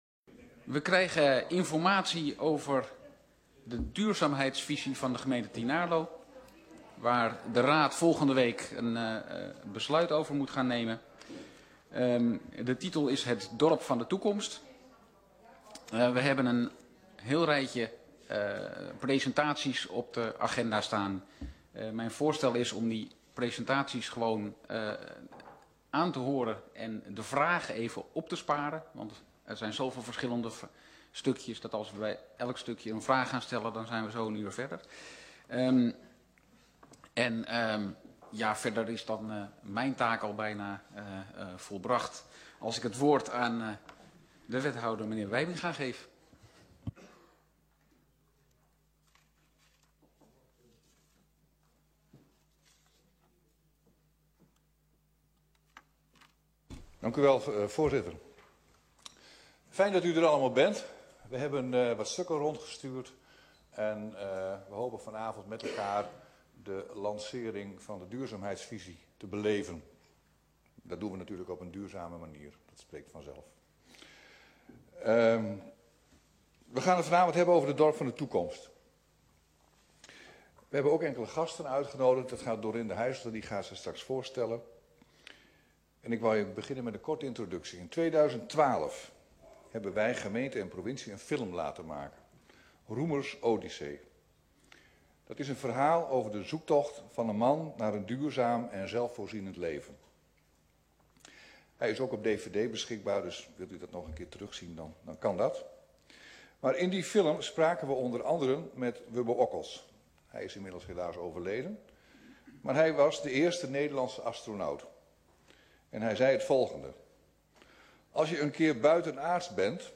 Locatie: Raadszaal
Opening door wethouder Theun Wijbenga
Gelegenheid voor vragen /discussie